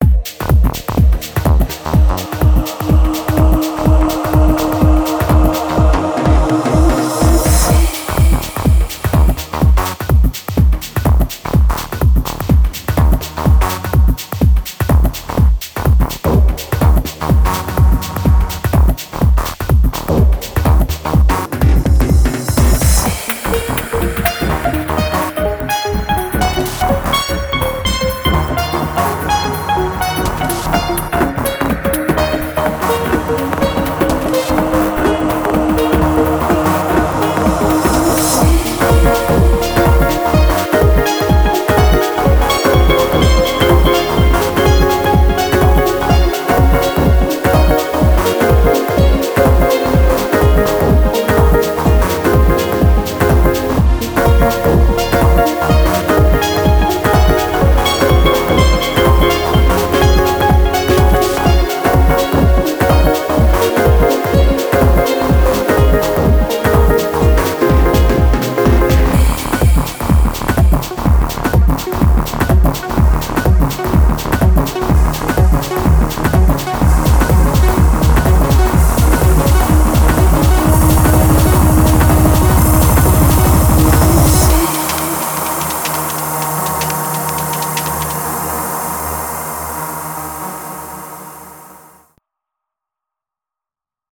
BPM125
Audio QualityMusic Cut